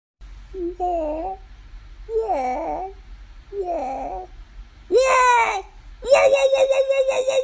crash-bandicoot-woah-original-without-post-processing-and-effects-green-screen-video.mp3